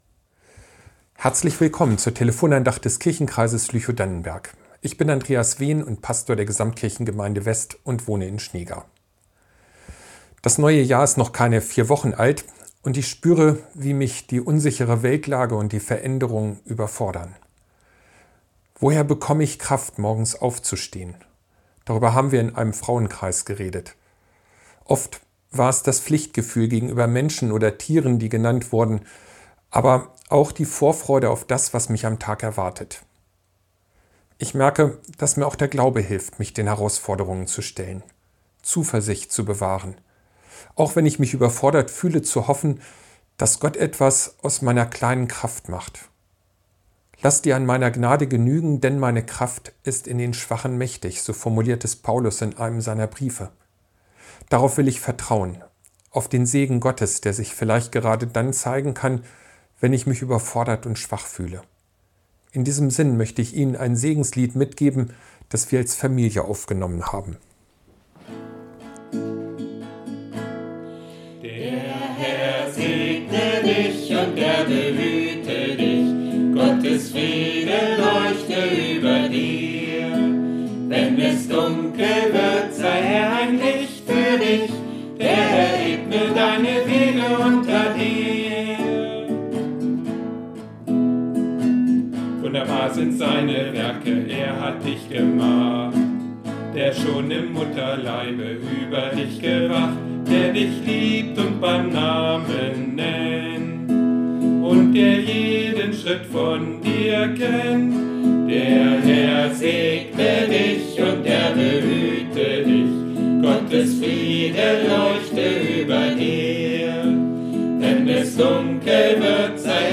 Telefon-Andacht